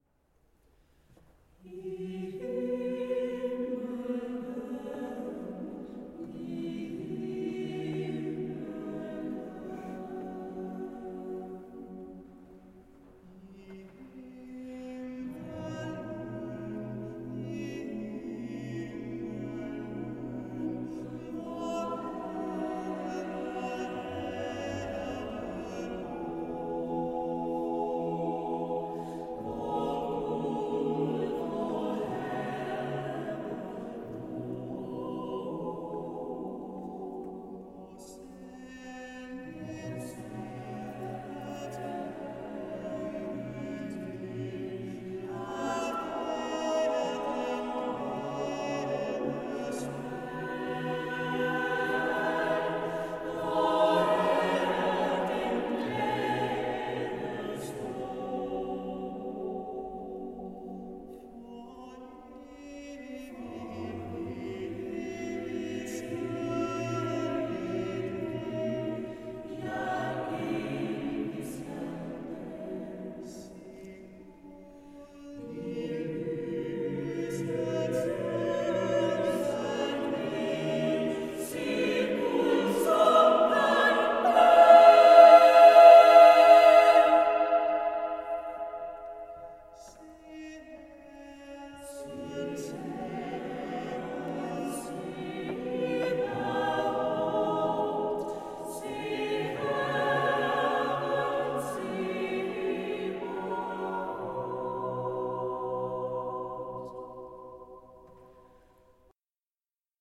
To dage senere gav Det Fynske Kammerkor en velbesøgt koncert i selve den store kirke “Overkirken”.
Fra koncerten kan her høres en optagelse af Dominus regit me: